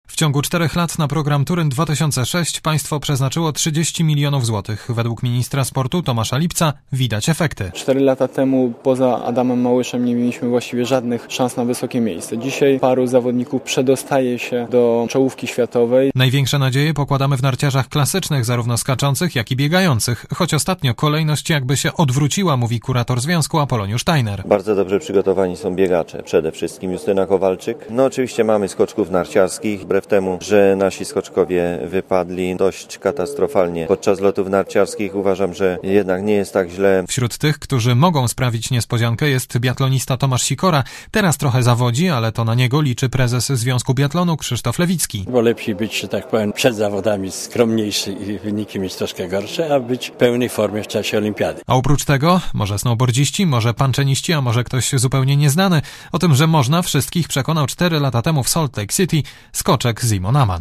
Źródło zdjęć: © Archiwum 17 stycznia 2006, 17:20 ZAPISZ UDOSTĘPNIJ SKOMENTUJ Relacja reportera Radia ZET